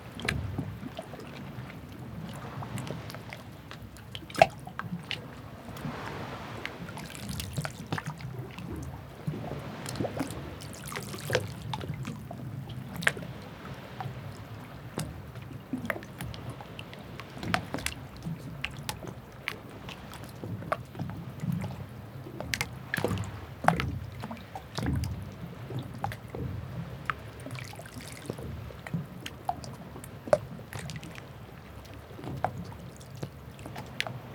Sea-water-churning-near-boat-01.wav